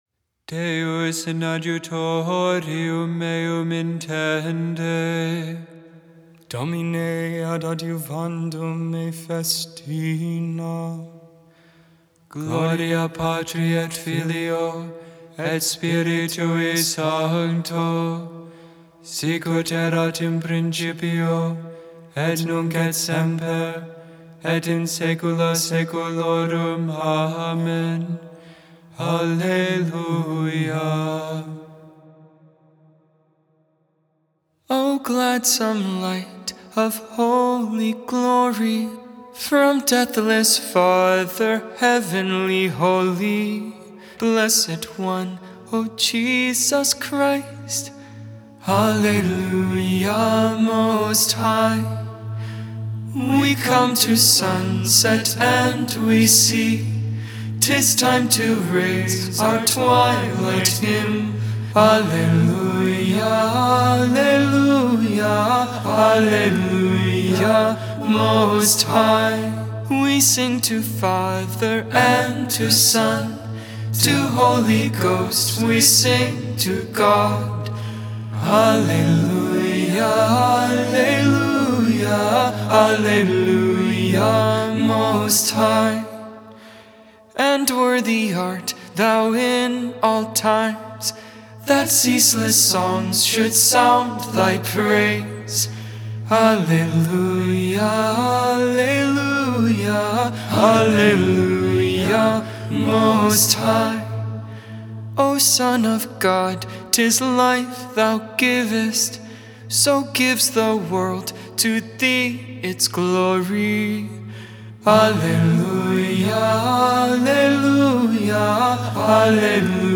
Gregorian tone 6
Gregorian tone 5